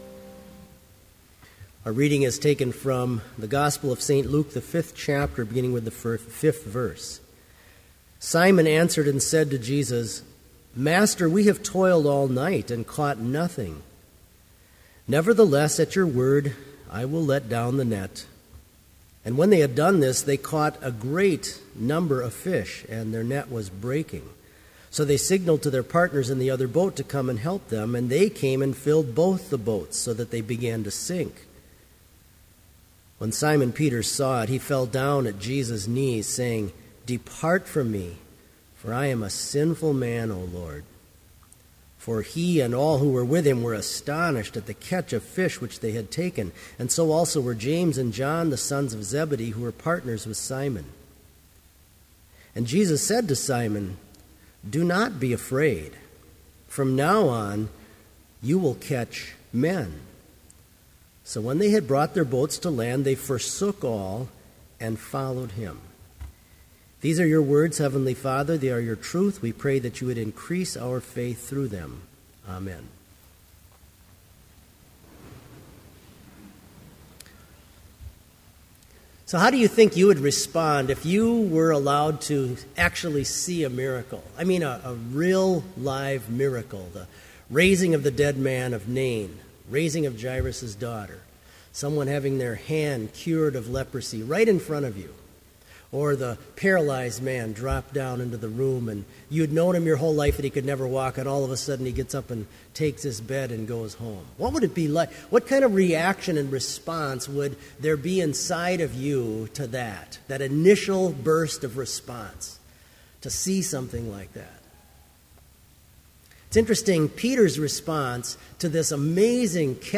Complete service audio for Summer Chapel - July 11, 2012